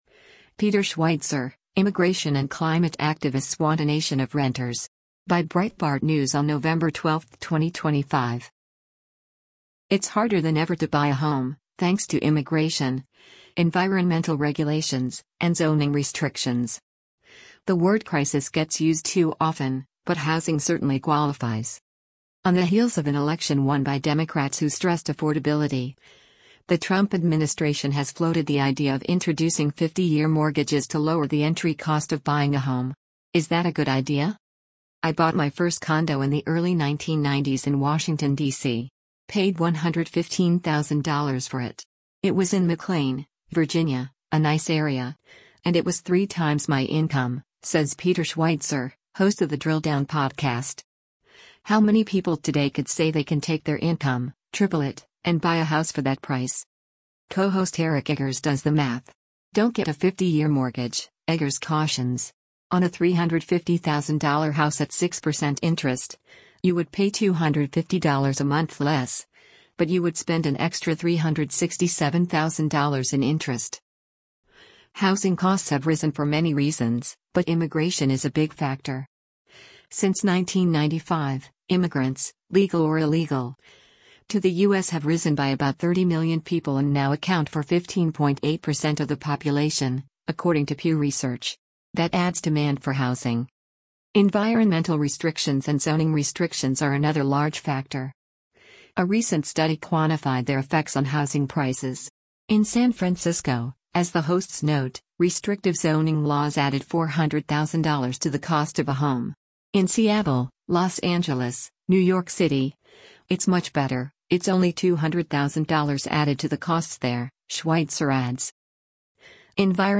In San Francisco, as the hosts note, restrictive zoning laws added $400,000 to the cost of a home.